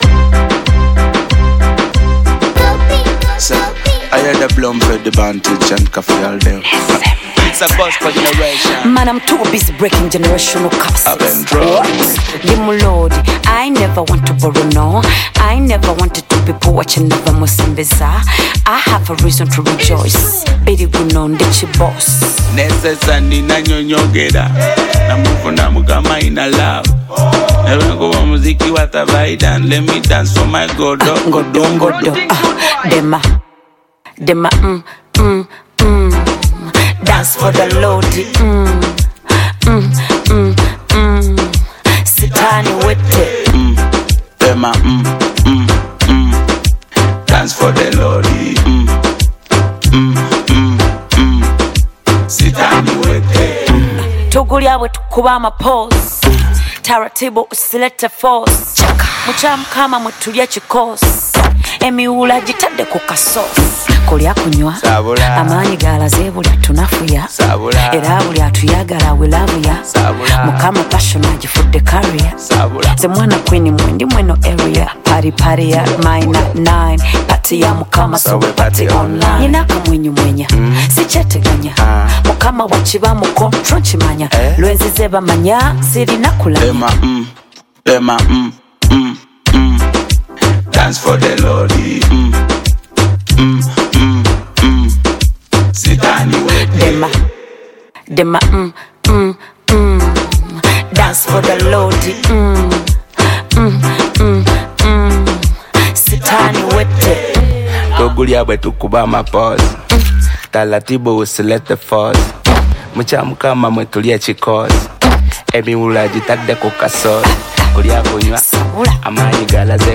delivers soulful vocals filled with gratitude
adds a deep gospel touch